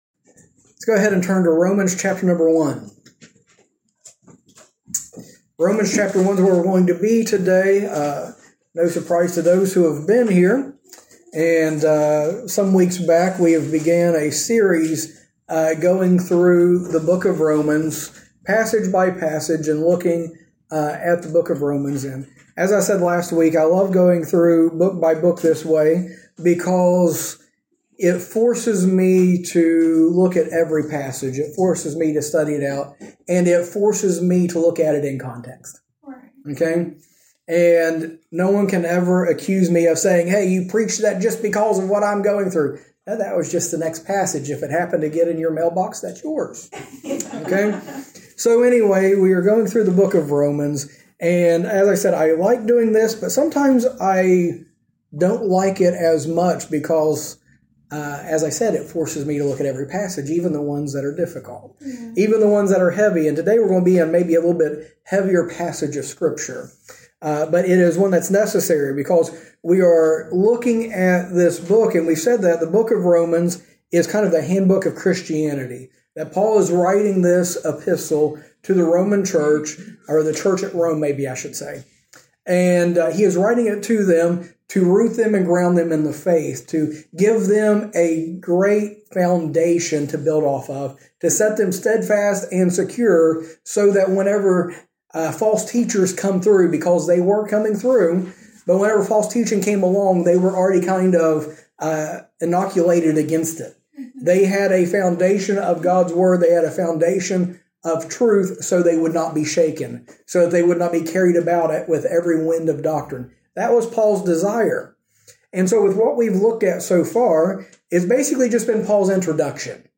In this sermon series, we go through Paul's letter to the Romans section by section as he shows how the Christian life should be.